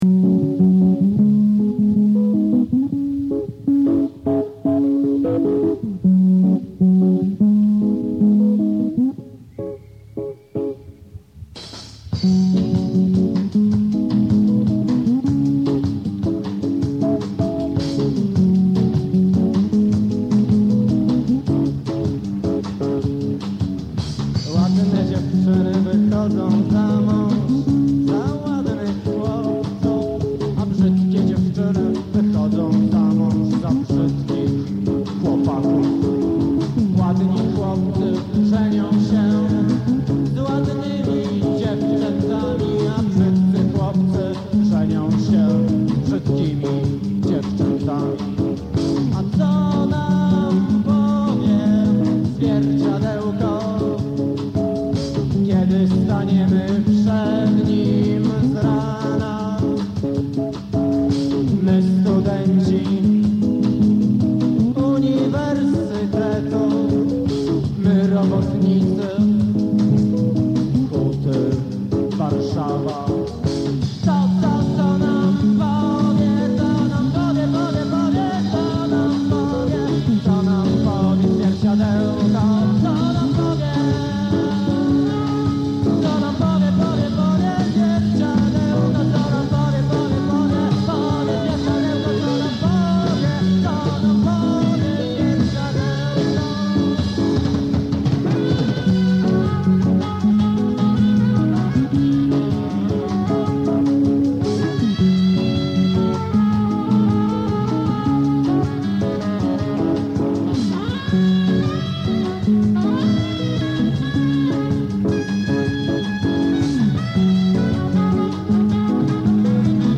gitara, wokal
perkusja
bas, wokal
saksofon altowy
Jakosc bootlegowa, niezla.